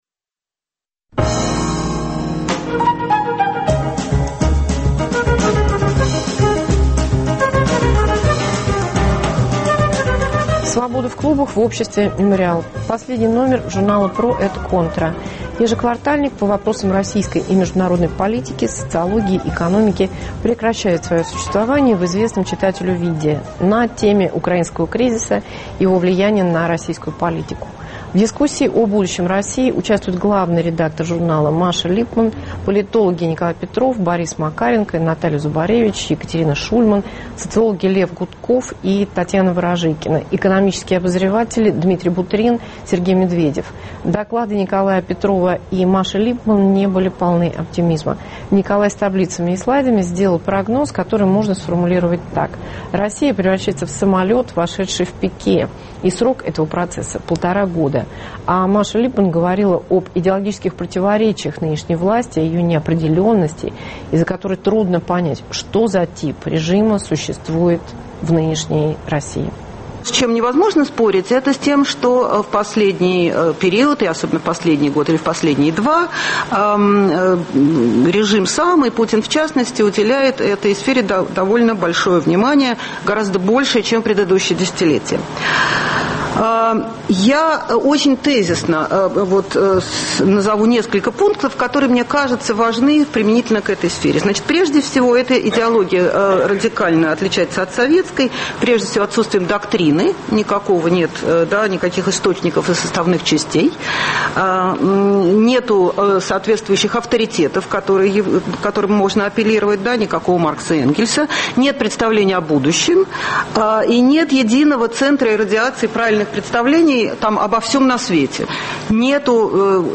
Свобода в Обществе “Мемориал”.